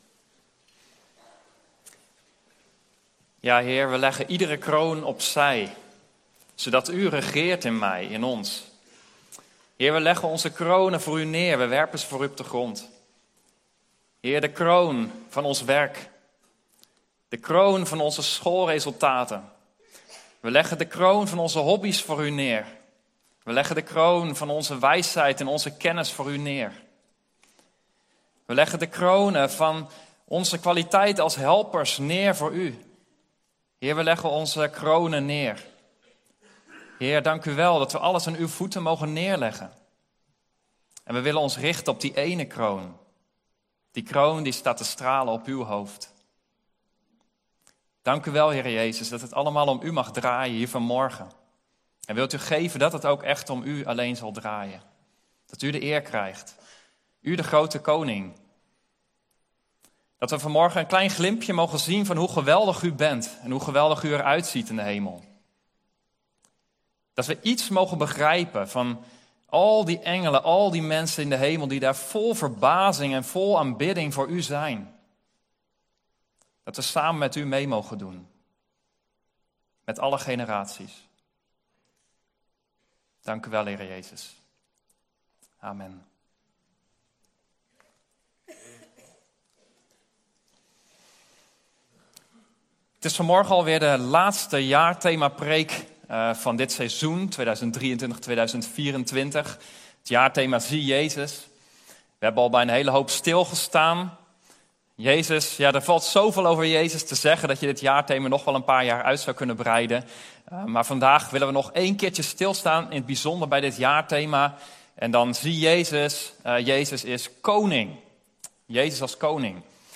Passage: Luk 19:28-42, Open 19:11-16 Soort Dienst: Reguliere dienst « Een ongeveinsd